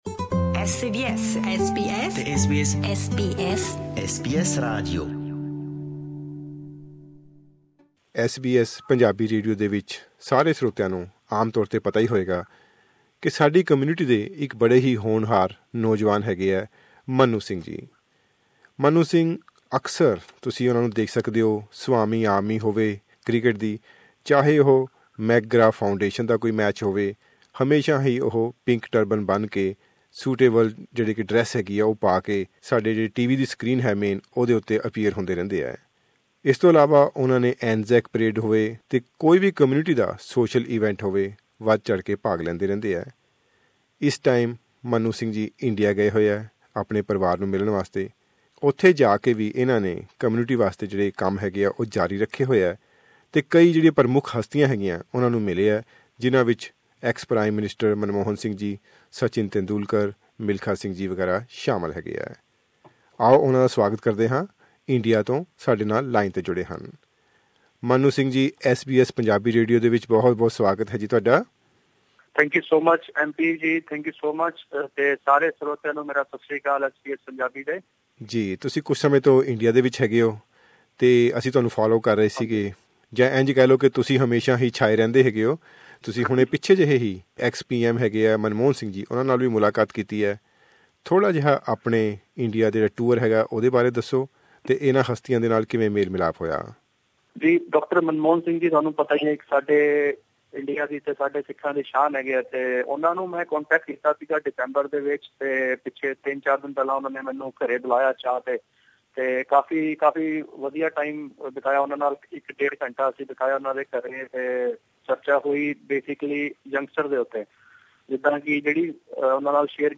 ਇਹ ਮੁਲਾਕਾਤ ਜਿਸ ਵਿੱਚ ਉਹ ਆਪਣੀਆਂ ਮੁਲਾਕਾਤਾਂ ਨੂੰ ਸੰਖੇਪ ਵਿੱਚ ਸਾਡੇ ਨਾਲ ਸਾਂਝਿਆਂ ਕਰ ਰਹੇ ਹਨ।